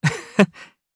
Siegfried-Vox_Happy1_jp.wav